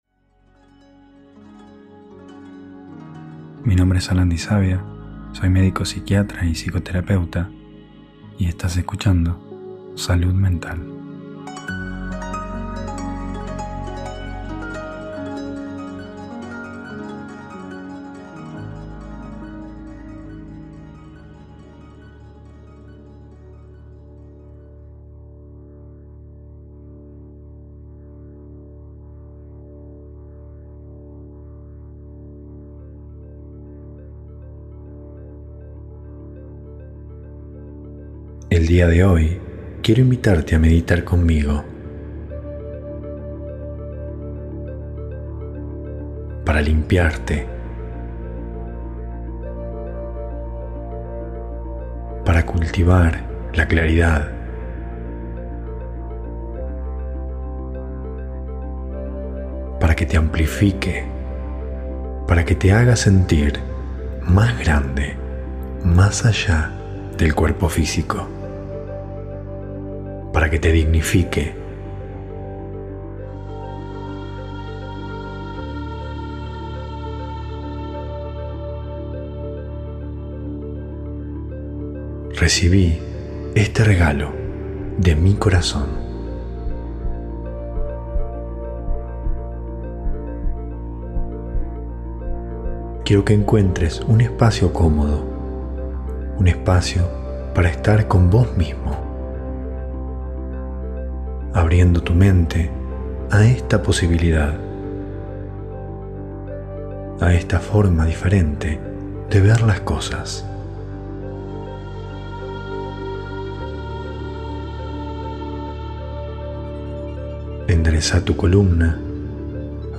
Meditación del SER ()
Meditación para escuchar 1/2 hora después de tomar tu microdosis. [Altamente recomendable escuchar con auriculares] Hosted on Acast.